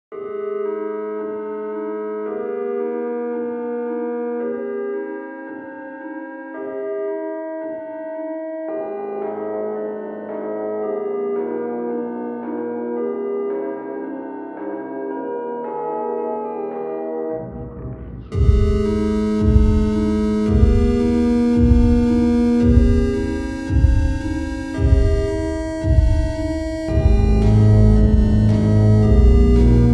Electronica Electronic
Жанр: Электроника